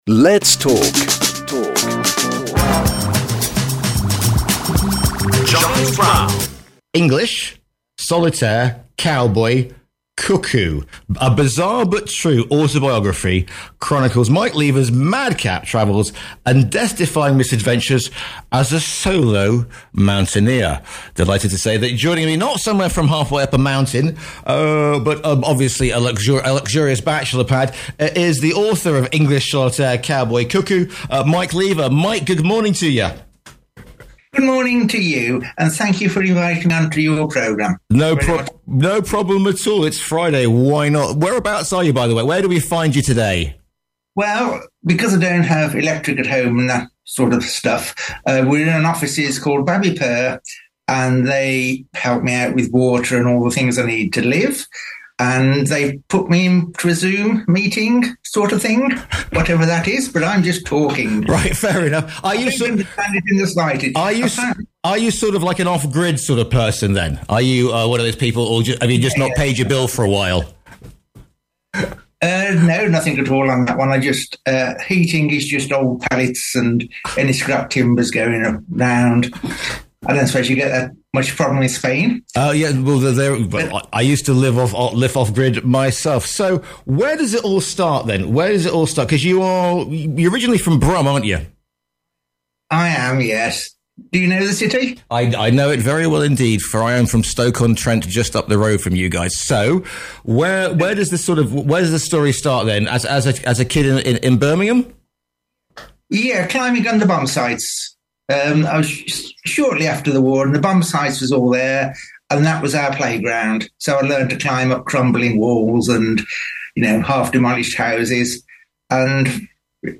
Similarly, there are two 30-minute interviews about my lifestyle, book-writing, and outdoor adventures on the following programmes broadcast recently: Talk Radio Europe